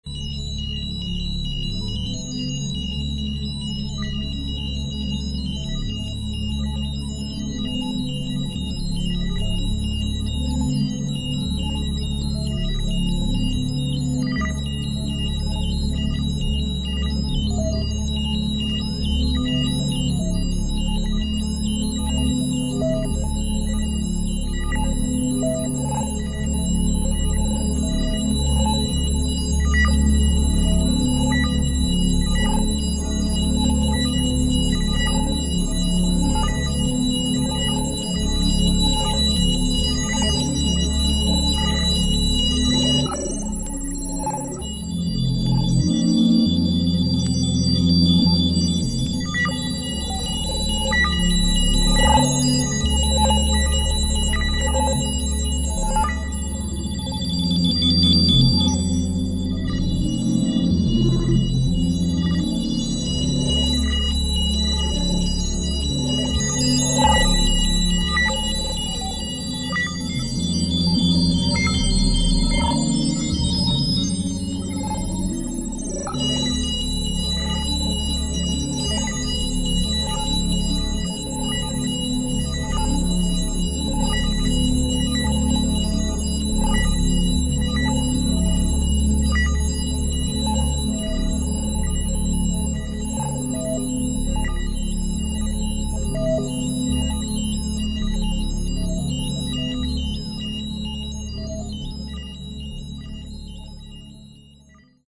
シンプルな編成ながら緻密で、まるで生きもののような深みを感じるエレクトロニクスの音像が堪能できます。